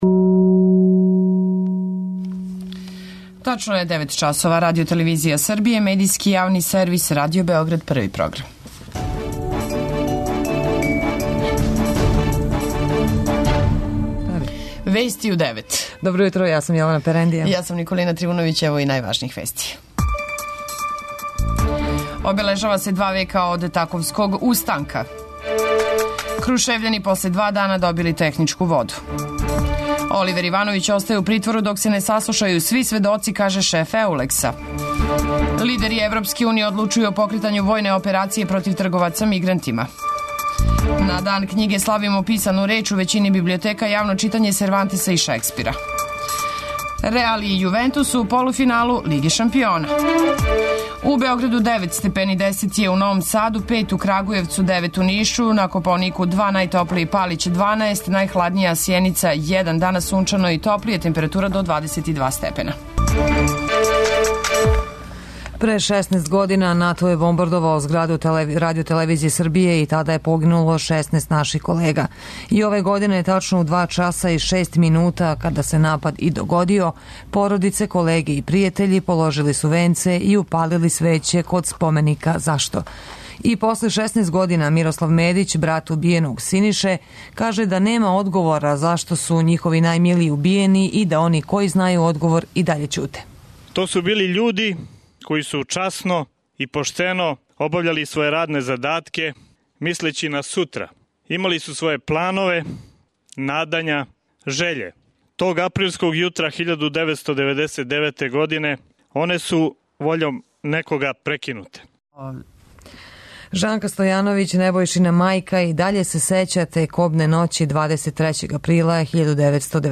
преузми : 9.99 MB Вести у 9 Autor: разни аутори Преглед најважнијиx информација из земље из света.